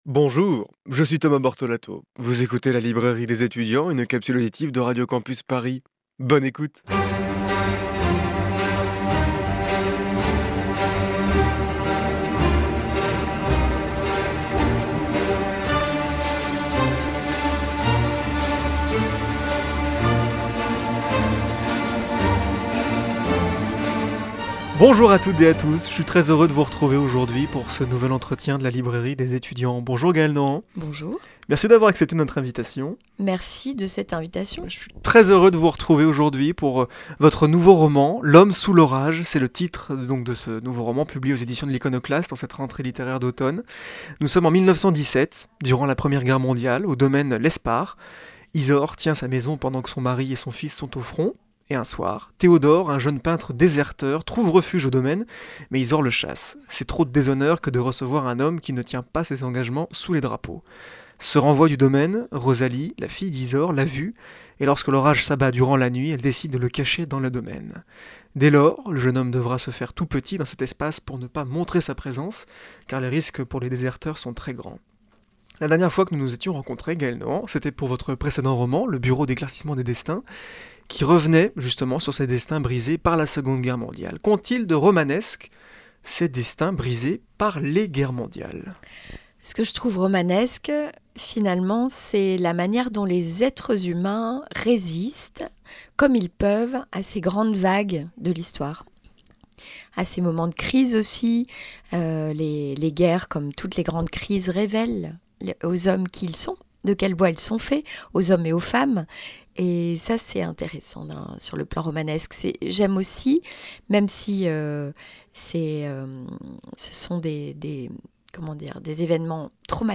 Pour cette nouvelle chronique de La Librairie des étudiants, je vous propose un entretien avec l'autrice Gaëlle Nohant. Son nouveau roman, L'homme sous l'orage est paru en cette rentrée littéraire aux éditions de l'Iconoclaste.